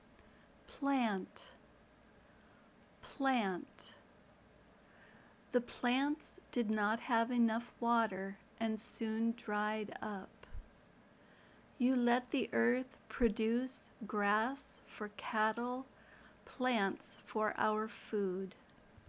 /plænt/ (noun)